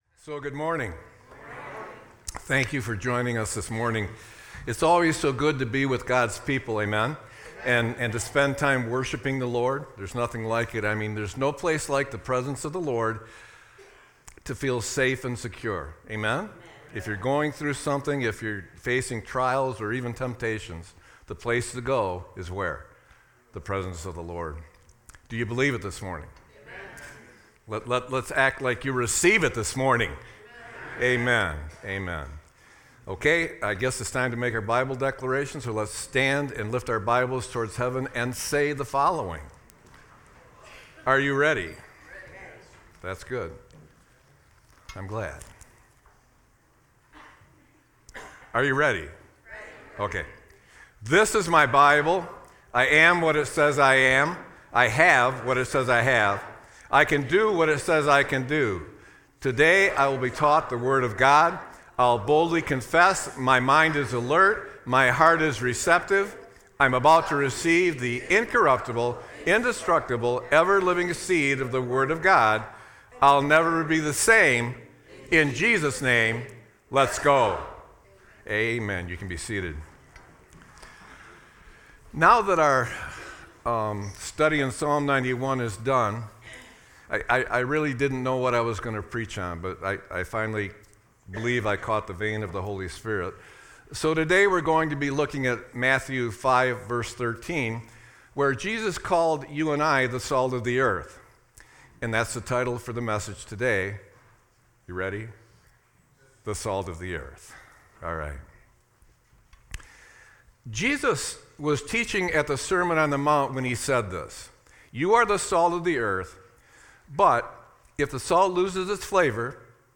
Sermon-3-16-25.mp3